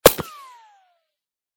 whine_10.ogg